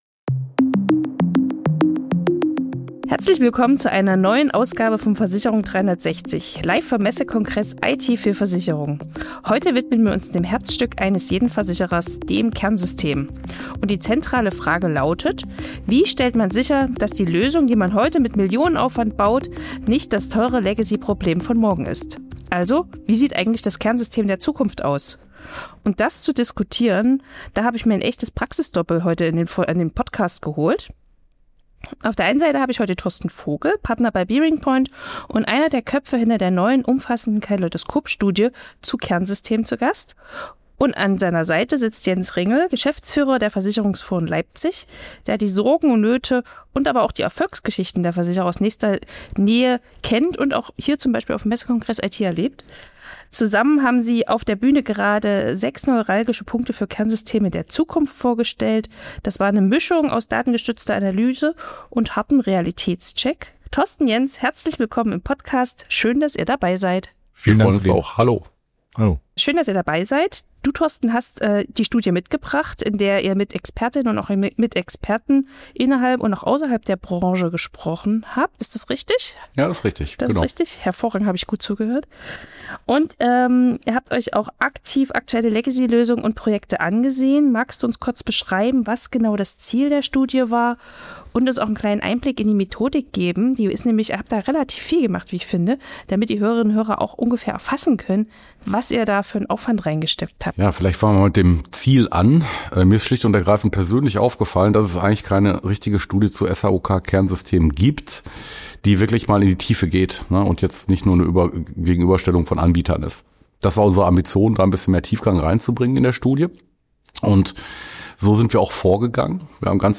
live vom Messekongress IT für Versicherungen